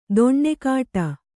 ♪ doṇṇe kāṭa